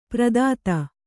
♪ pradāta